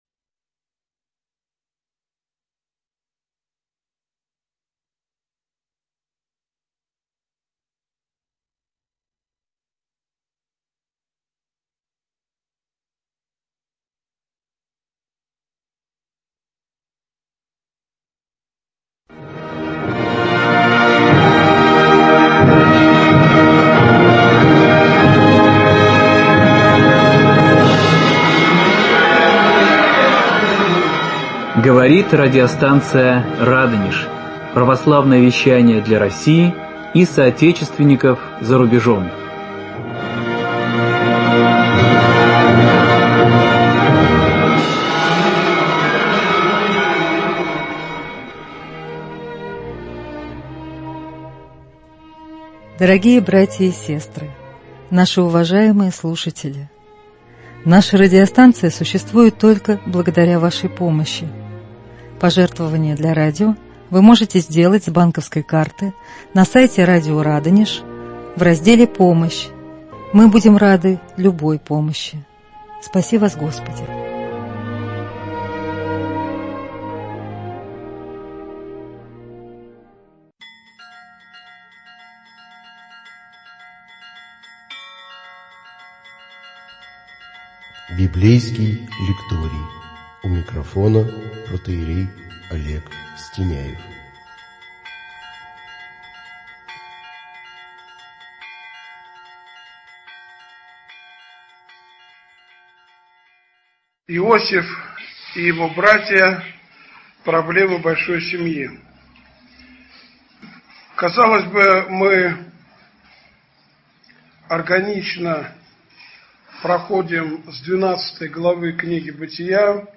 Иосиф Прекрасный и его братья. Проблемы большой семьи. Беседа 1